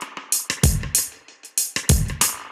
Index of /musicradar/dub-designer-samples/95bpm/Beats
DD_BeatA_95-02.wav